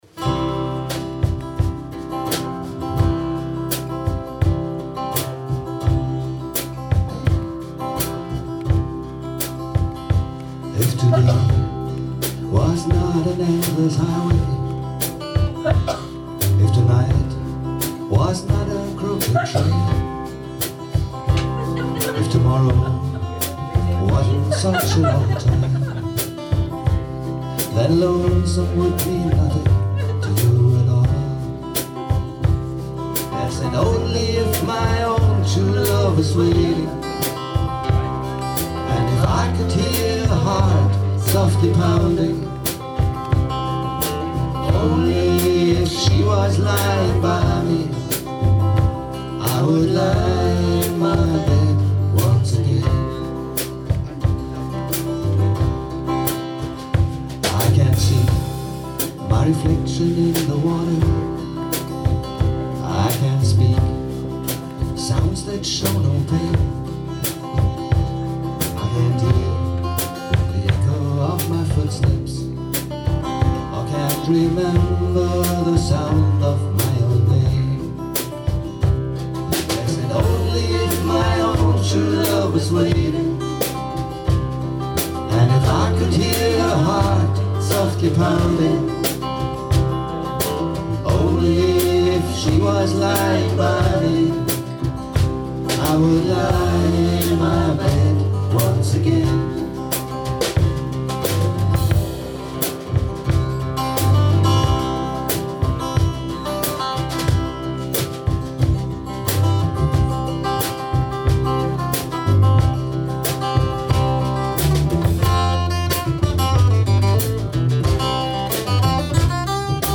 vocals,guitars,lapsteel,harp
bass/kontrabass/vocals
drums/cajon/vocals